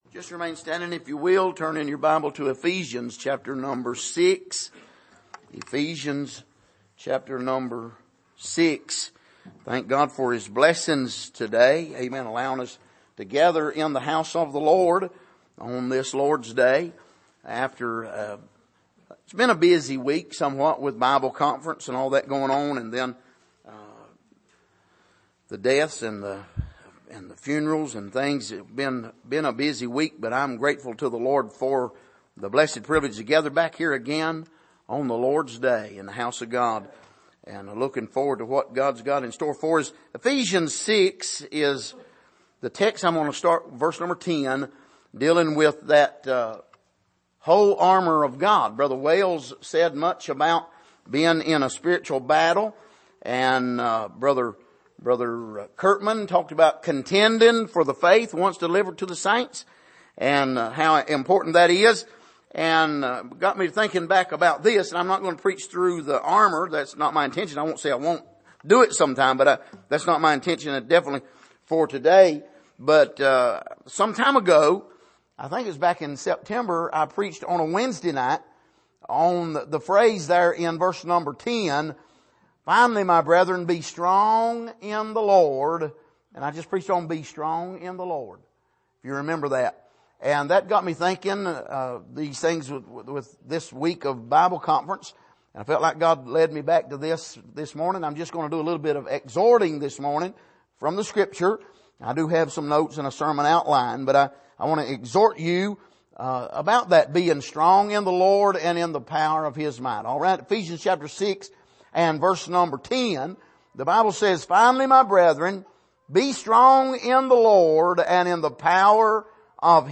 Passage: Ephesians 6:10-24 Service: Sunday Morning